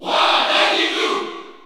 Crowd cheers (SSBU) You cannot overwrite this file.
King_Dedede_Cheer_French_PAL_SSBU.ogg